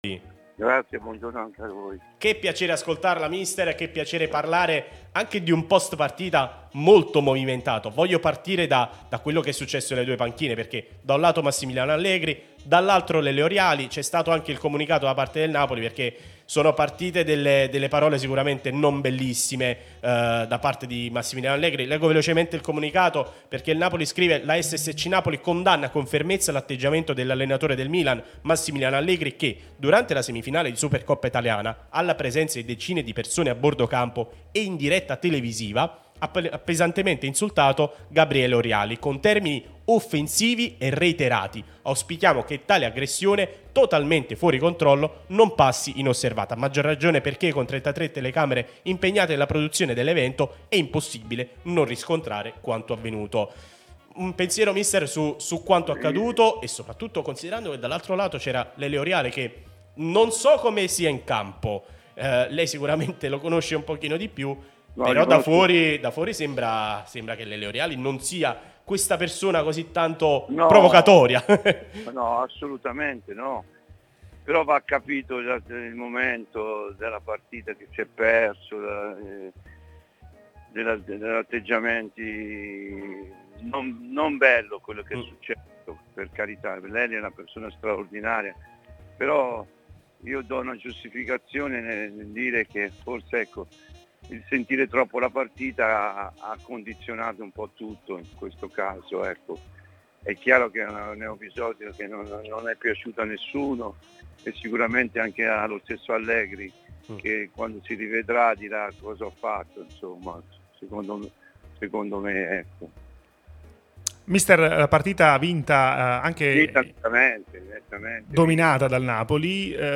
Walter Novellino, ex Napoli e Milan, è intervenuto nel corso de Il Bar di Tuttonapoli, trasmissione sulla nostra Radio Tutto Napoli, prima radio tematica sul Napoli, in onda tutto il giorno, che puoi ascoltare/vedere qui sul sito o sulle app (qui per Iphone/Ipad o qui per Android).